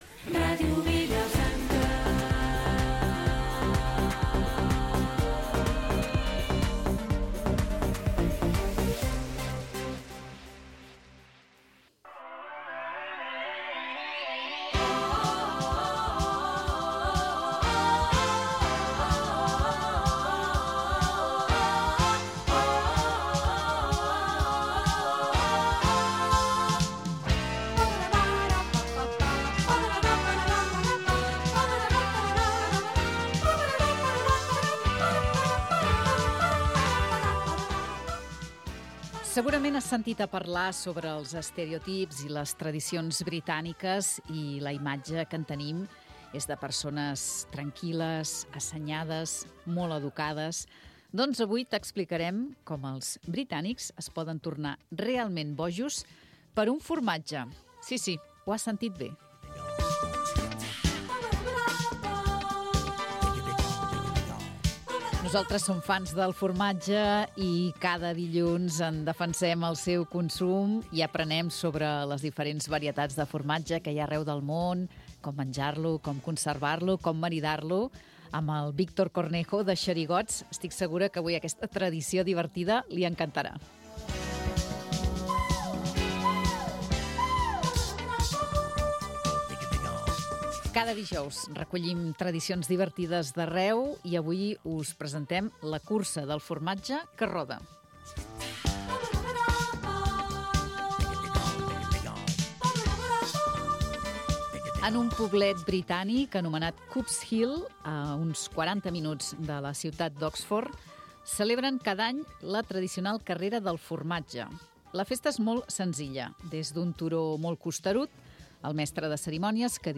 Magazín diari d'estiu